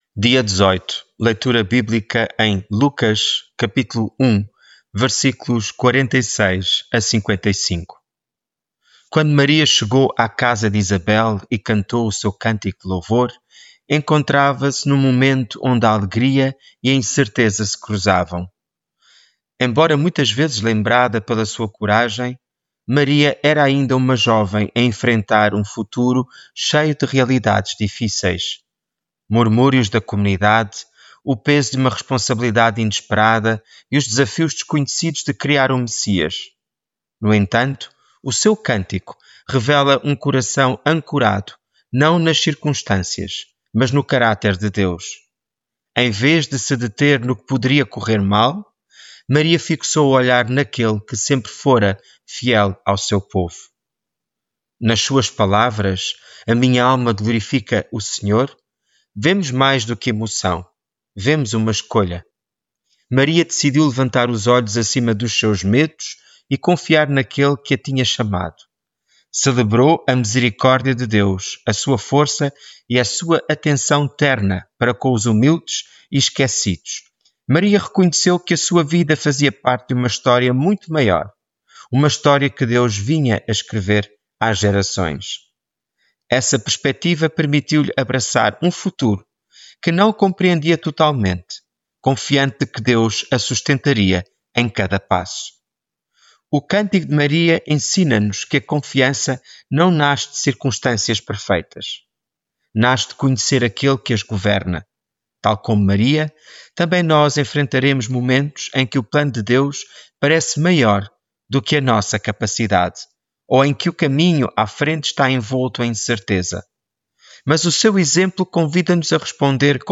Devocional
Leitura bíblica em Lucas 1:46-55